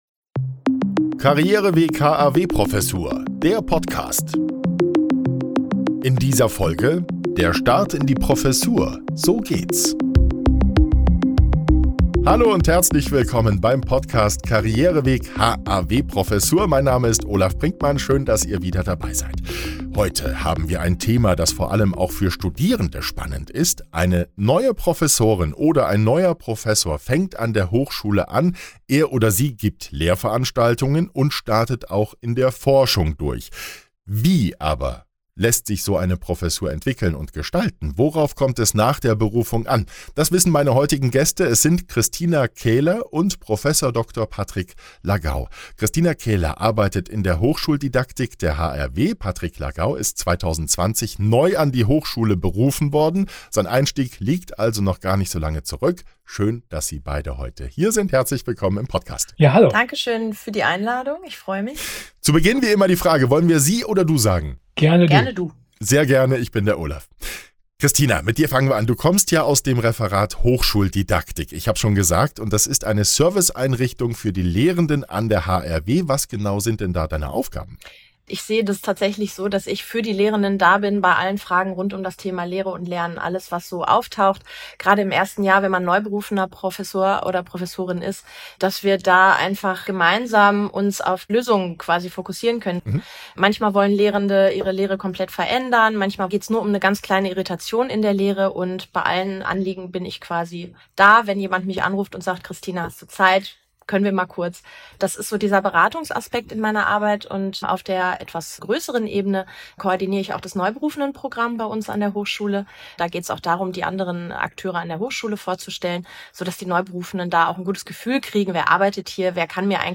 Das wissen die Gäste dieser Episode.